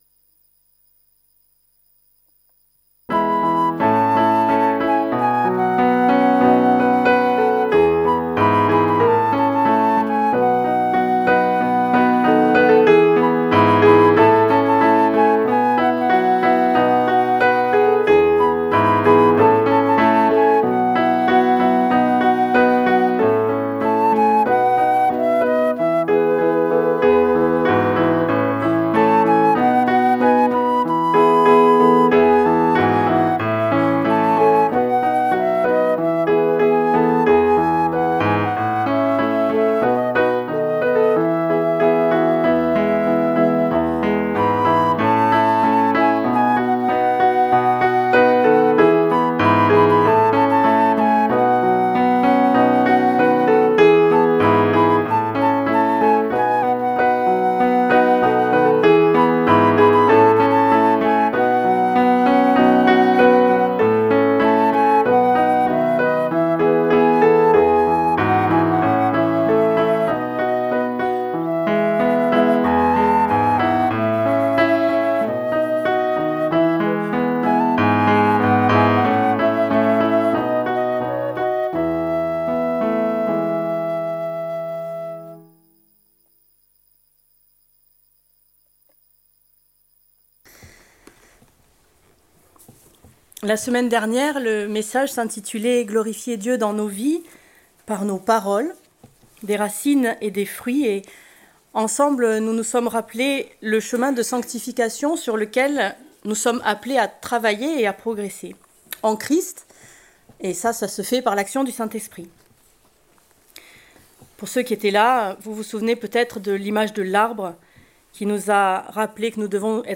Prédication du 09 MARS 2025.